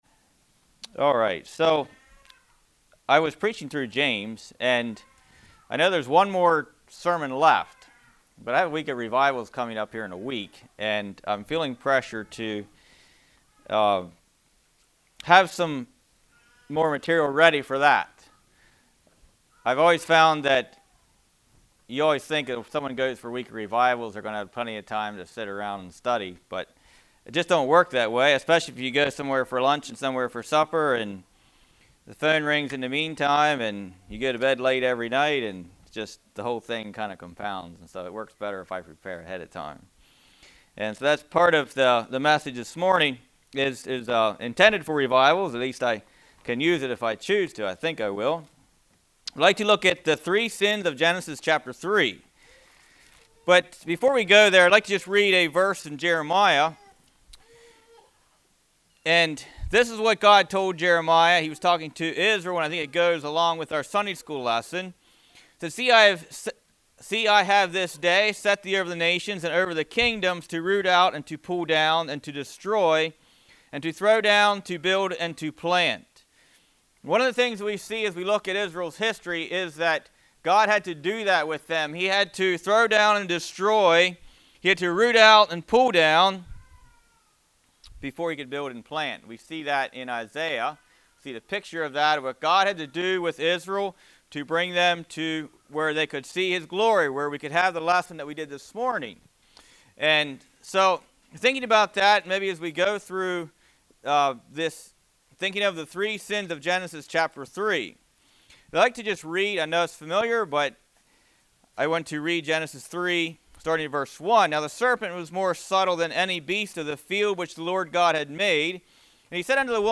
Congregation: Winchester
Sermon